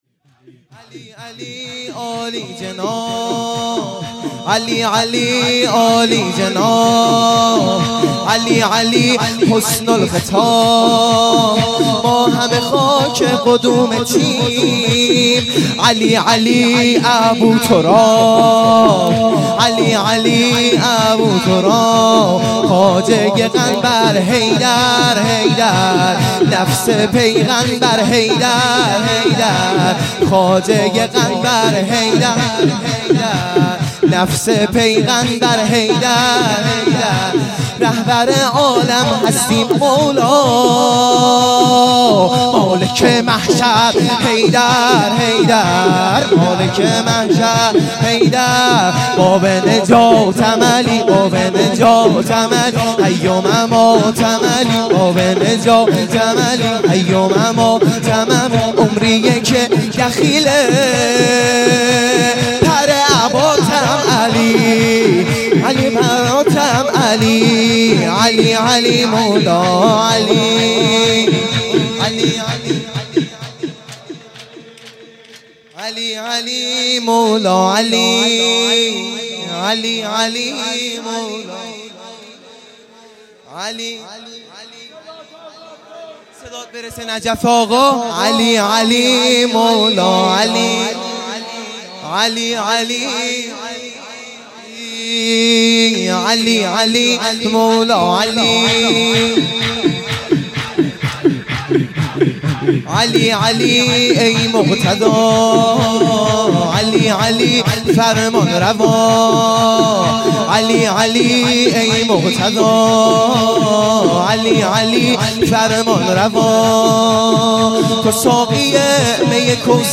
شور | علی علی عالیجناب